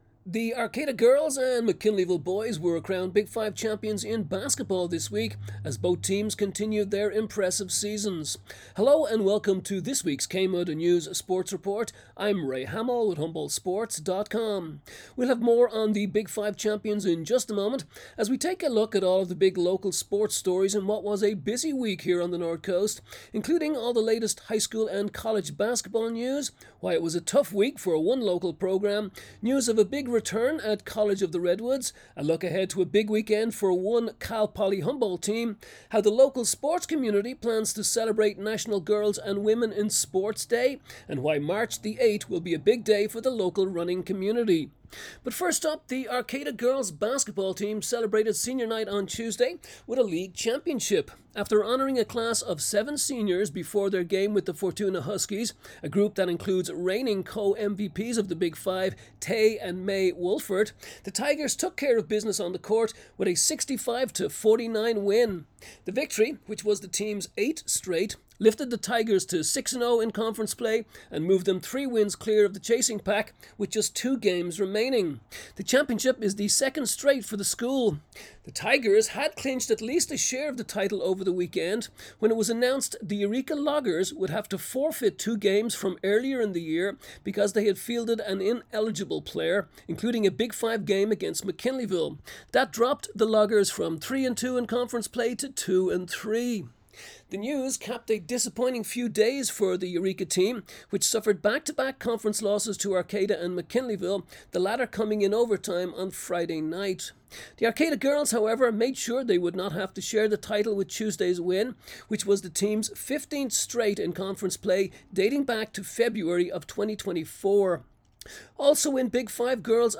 KMUD News Sports report Jan 29